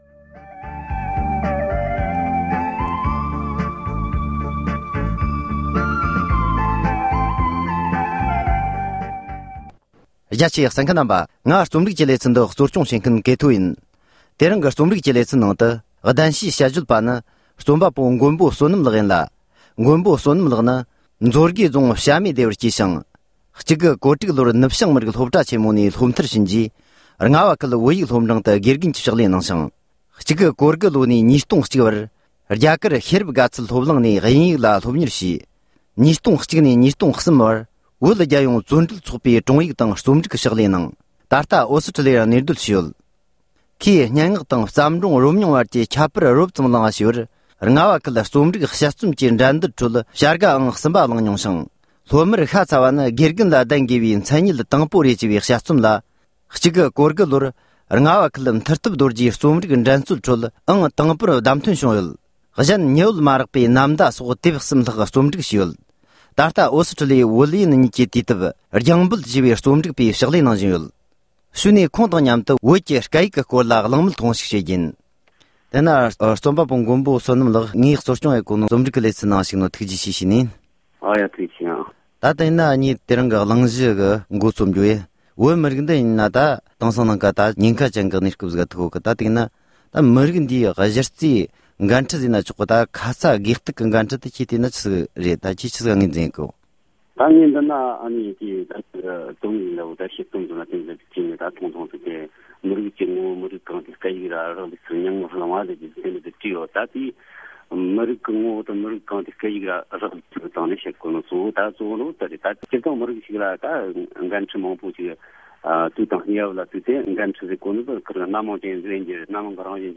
བཅར་འདྲི་བྱས་པ།